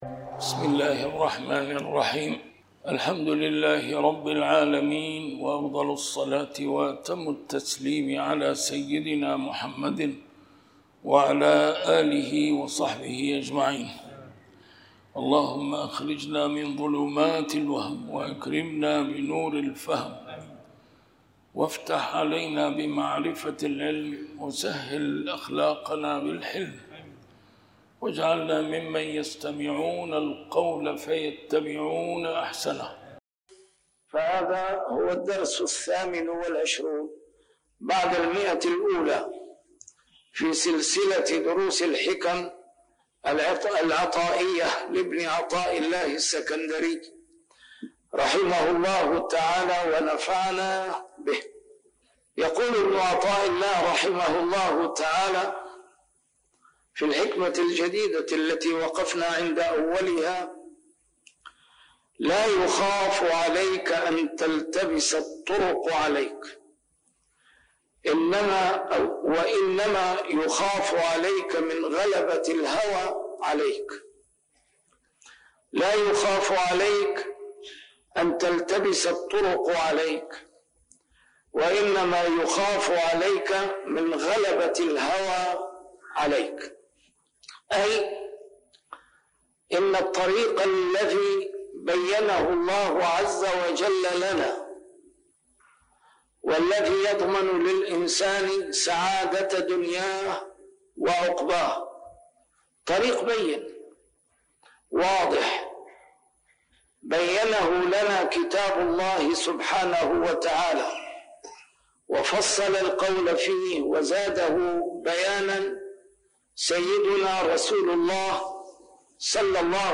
A MARTYR SCHOLAR: IMAM MUHAMMAD SAEED RAMADAN AL-BOUTI - الدروس العلمية - شرح الحكم العطائية - الدرس رقم 128 شرح الحكمة 107